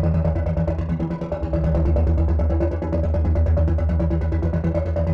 Index of /musicradar/dystopian-drone-samples/Tempo Loops/140bpm
DD_TempoDroneE_140-E.wav